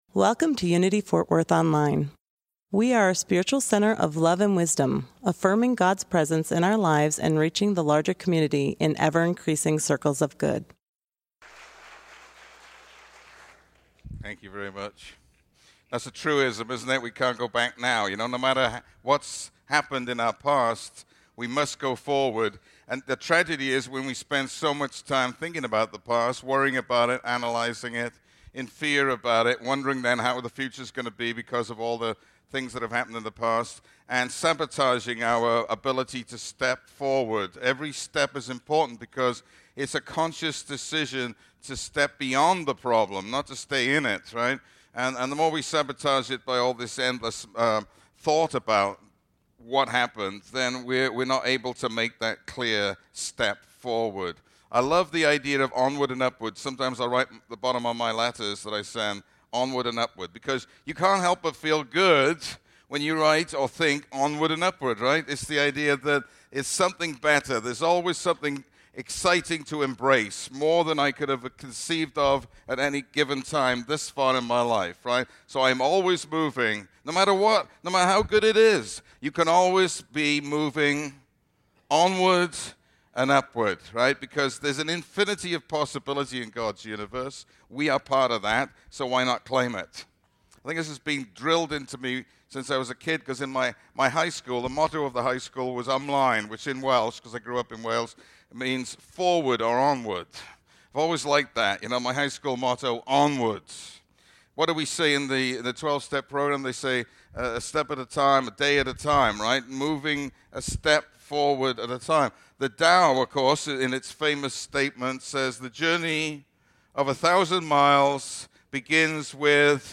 In this Palm Sunday message we discuss ways in which we can step forward effectively in our lives as we celebrate the amazing demonstration of Jesus Christ.